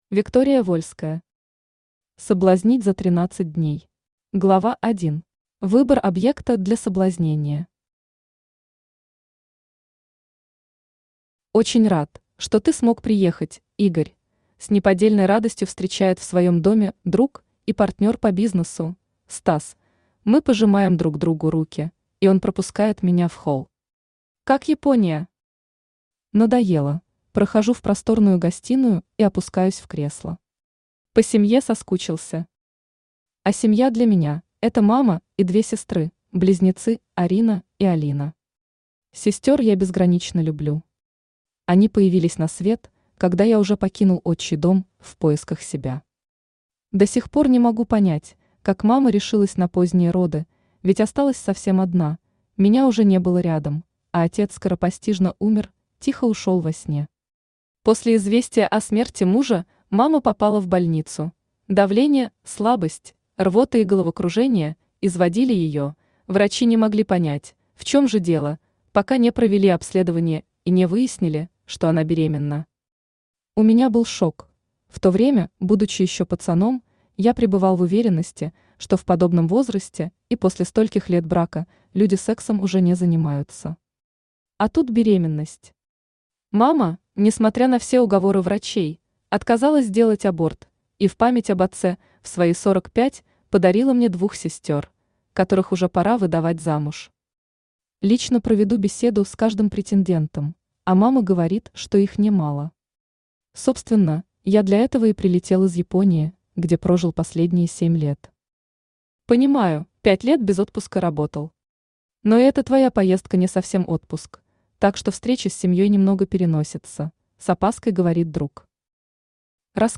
Аудиокнига Соблазнить за 13 дней | Библиотека аудиокниг
Aудиокнига Соблазнить за 13 дней Автор Виктория Вольская Читает аудиокнигу Авточтец ЛитРес.